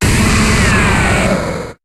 Cri de Steelix dans Pokémon HOME.